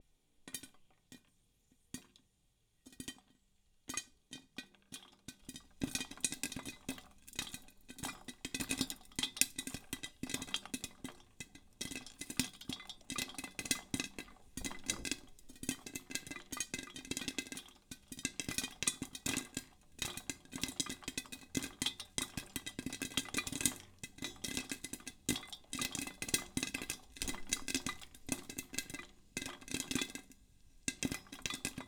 popping-popcorn.wav